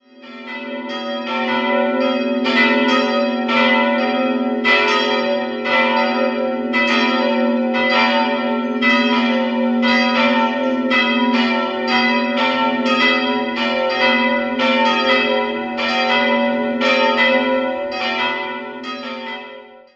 3-stimmiges Geläute: ais'-h'-dis'' Die drei Glocken wurden 1768 von Pascolini in Ingolstadt gegossen.